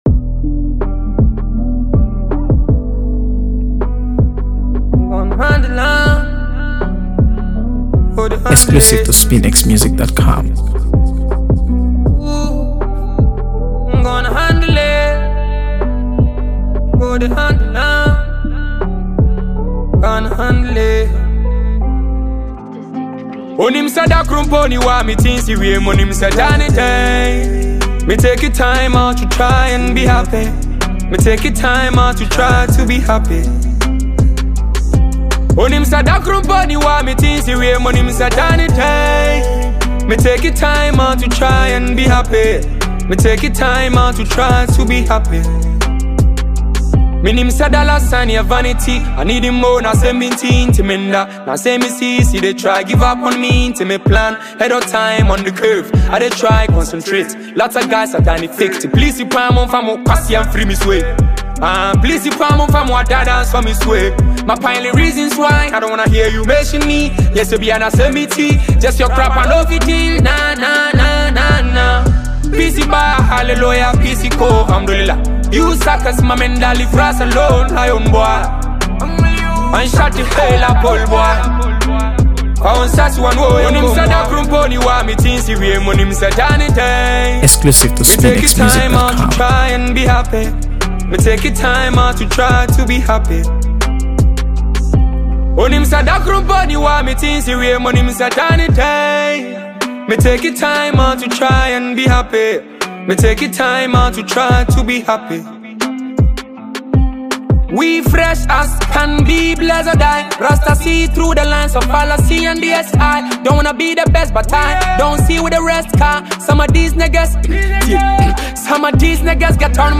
AfroBeats | AfroBeats songs
Ghanaian singer-songwriter